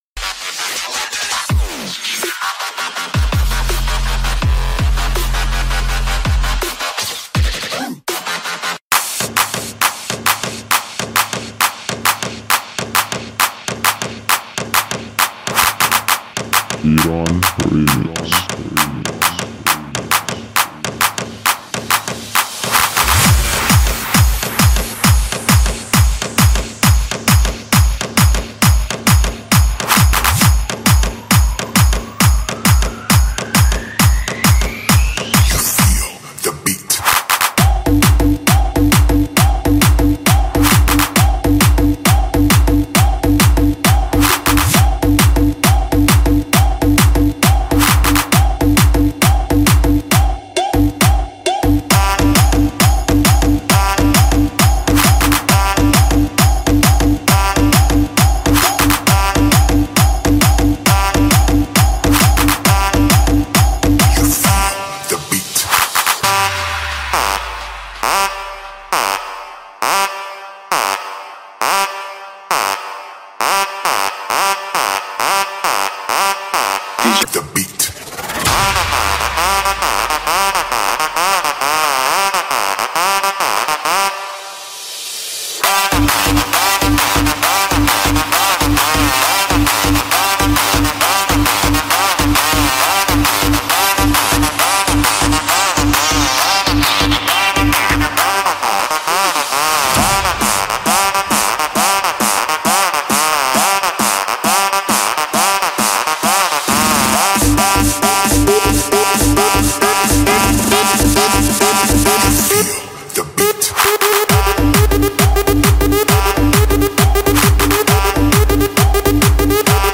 ریمیکس
دانلود تریبال جدید ۲۰۱۹